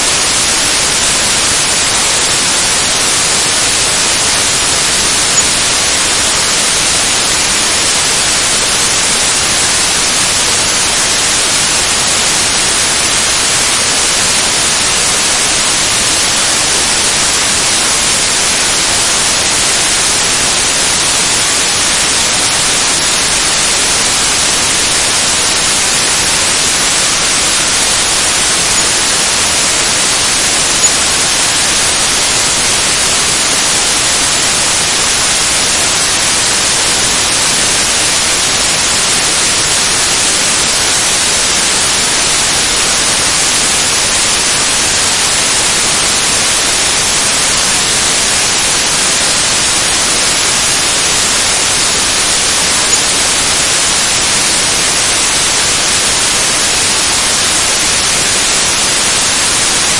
布朗噪声30秒
描述：布朗的噪音。
标签： 大声的 静态的 随机的 电视 音响 背景 ATMO 游戏 手机 恐怖 布朗 噪音 氛围 数字 ATMOS 干扰 电视 无信号 薄膜 电子 环境 无线电 多风 毛刺 流行 氛围 布朗噪声 气氛
声道立体声